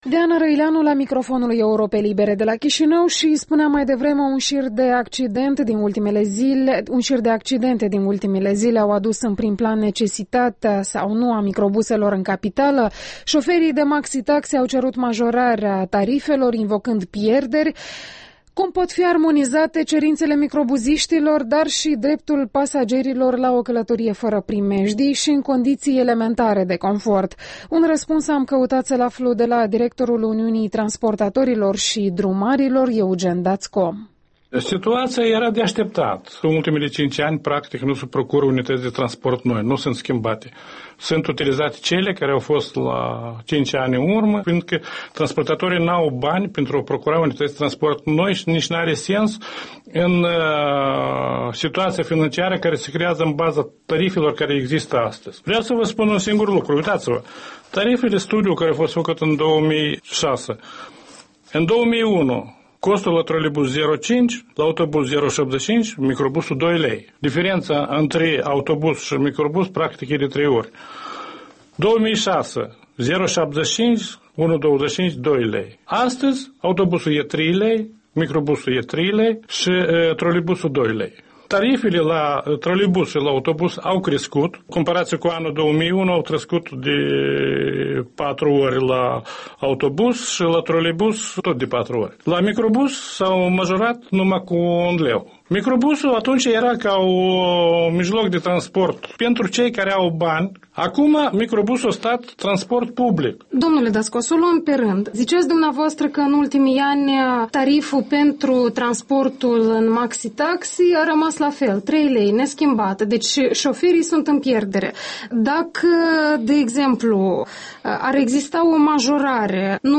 Interviul dimineții: despre transportul în comun cu